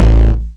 gtdTTE67029guitar-A.wav